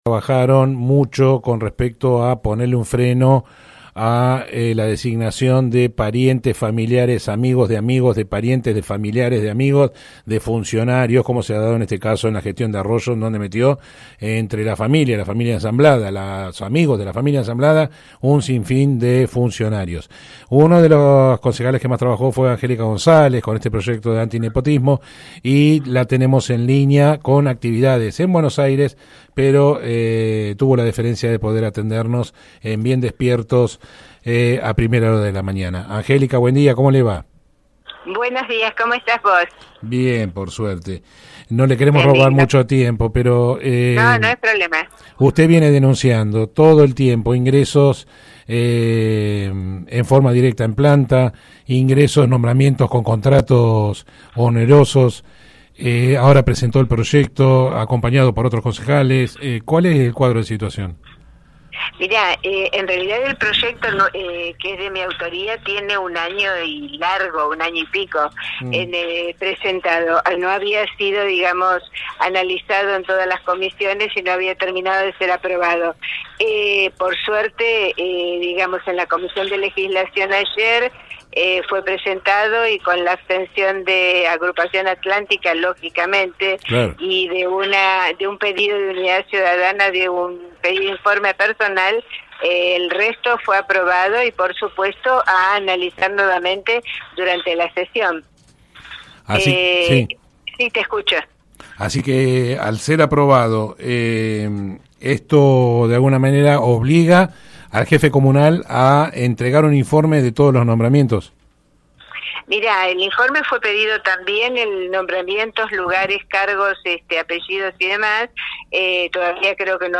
Angélica González, concejala del bloque Coalición Cívica e impulsora del proyecto, dijo a Bien Despiertos, programa emitido de 7 a 9, por radio De la Azotea, que “se abstuvo, obviamente, el bloque de Agrupación Atlántica y Unidad Ciudadana pidió un informe personal”.